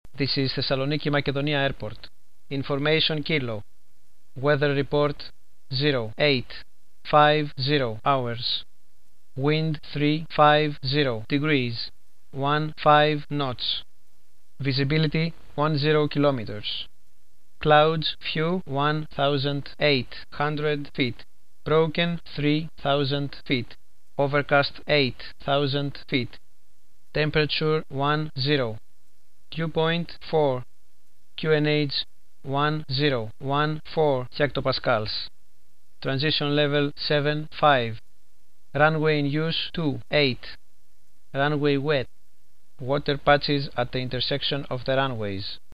(compression may decrease output quality)